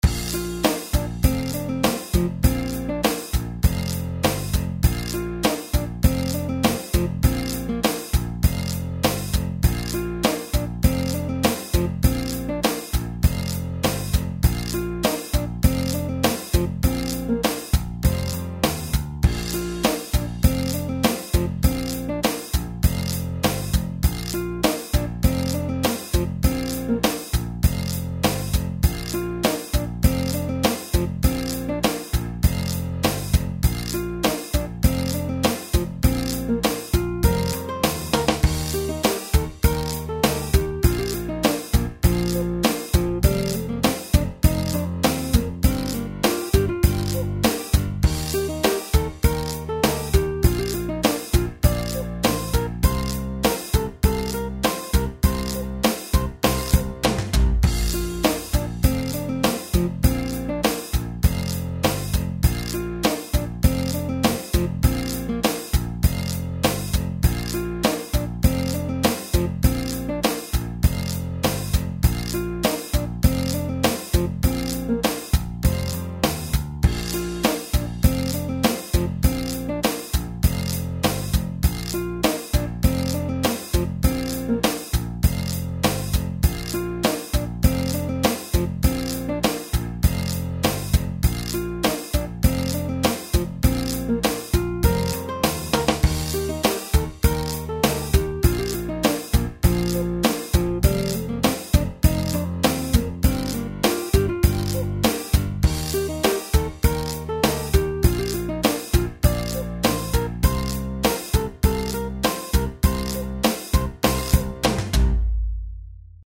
ズンチャ、ズンチャ、みたいなのんびりしたやつ作りたいなーと思って、パパっと。